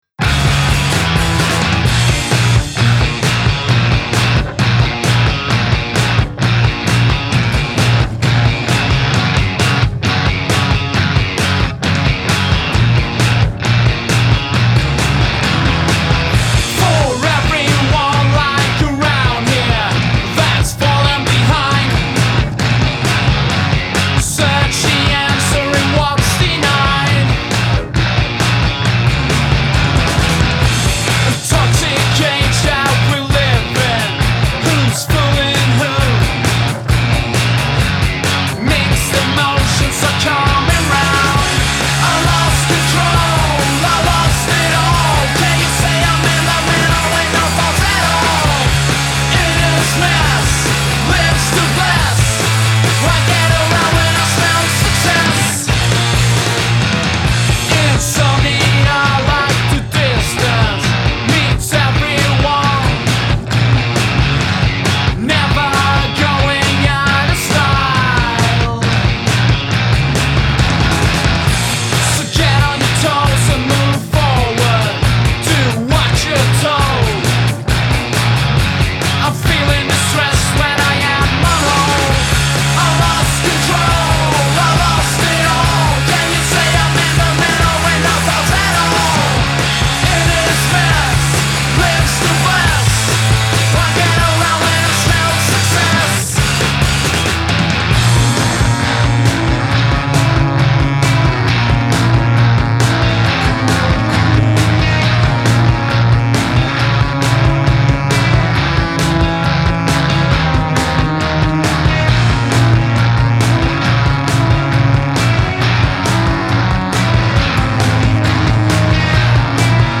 And yeah, support your (local) noiserock band!